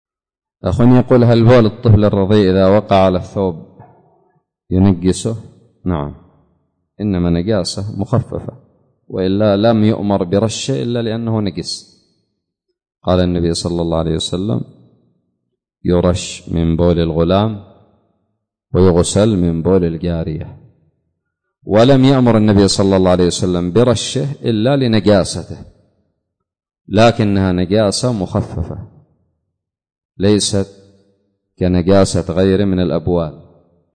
سؤال قدم لفضيلة الشيخ حفظه الله